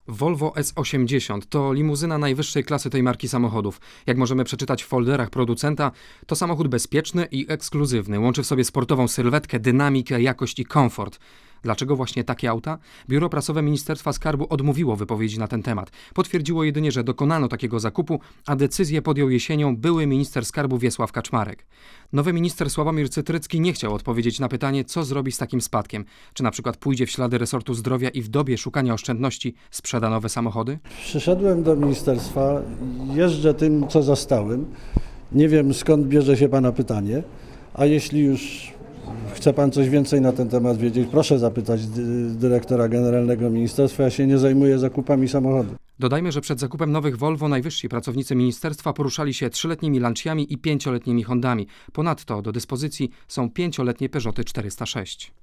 Posłuchaj reportera Radia Zet